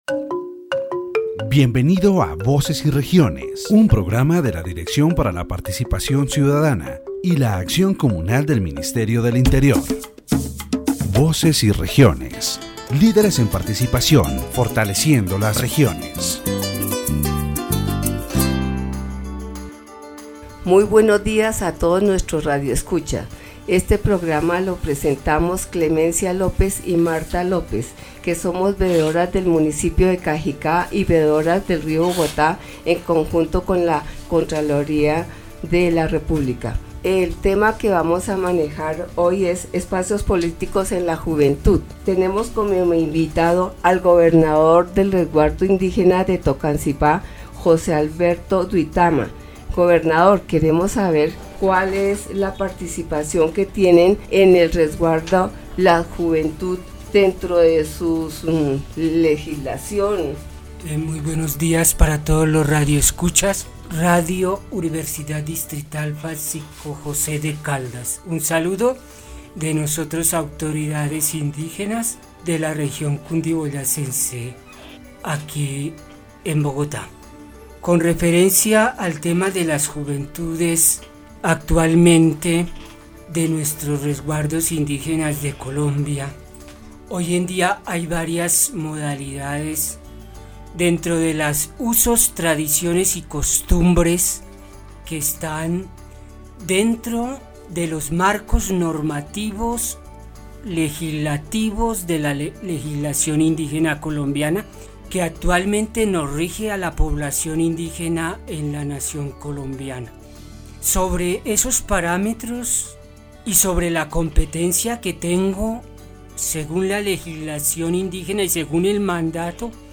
In this section of the Voces y Regiones program, the interviewee discusses the youth in Colombia's Indigenous reserves, highlighting their customs, rights, and legislation that protect them.